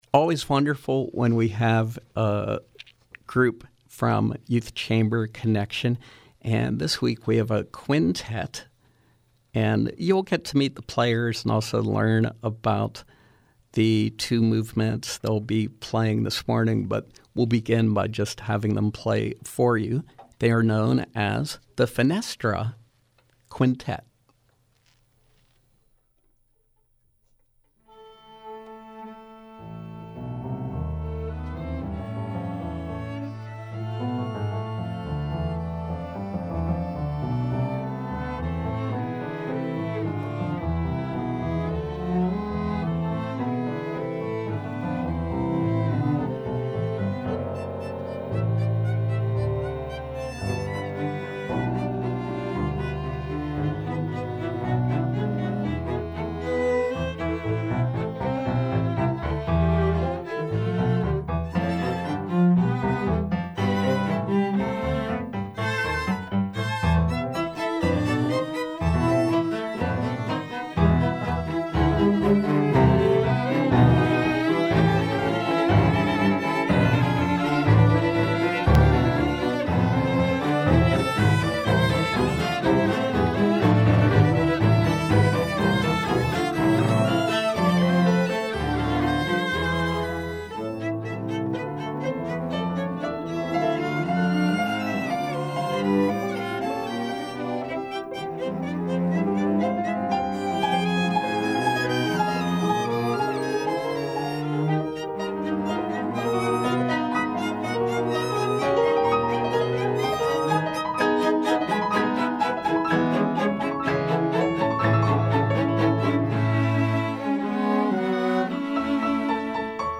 violin
piano
viola
cello